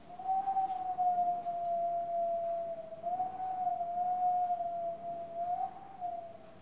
Les sons ont été découpés en morceaux exploitables. 2017-04-10 17:58:57 +02:00 1.1 MiB Raw History
vent_01.wav